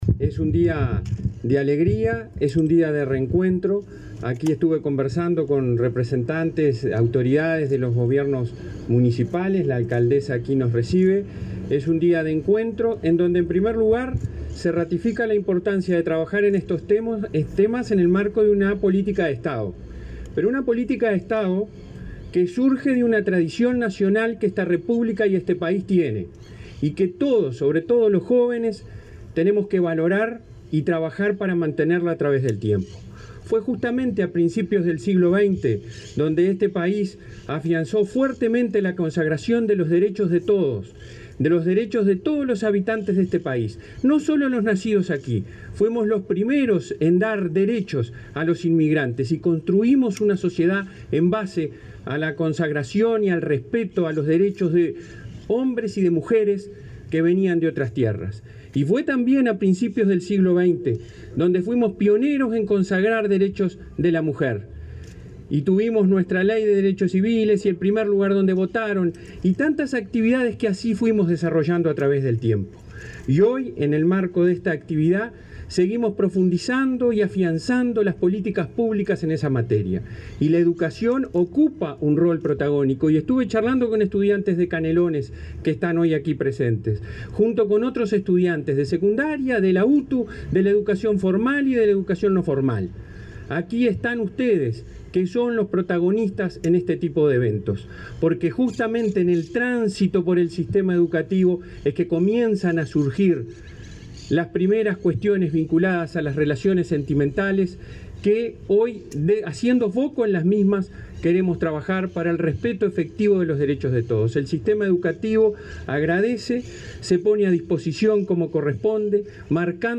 Palabras del presidente del Codicen de la ANEP, Robert Silva
El presidente del Consejo Directivo Central (Codicen) de la ANEP, Robert Silva, participó del lanzamiento de la campaña Noviazgo Libre de Violencia,